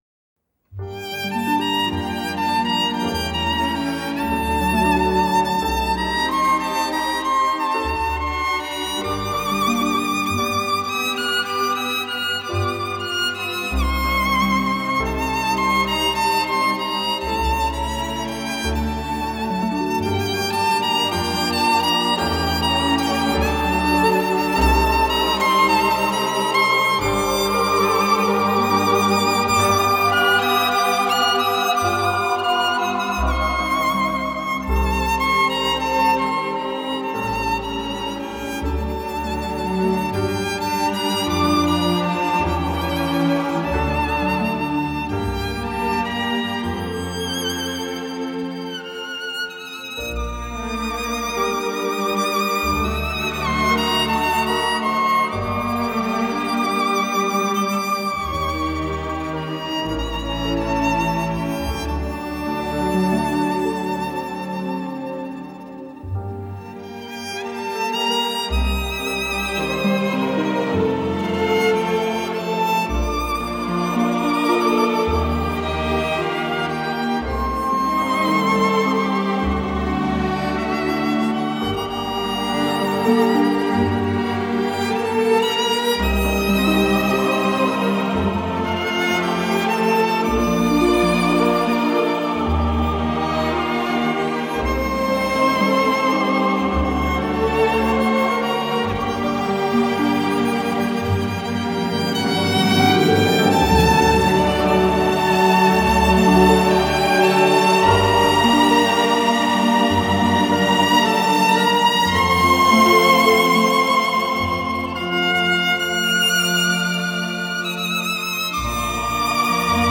[نوع آهنگ: لایت]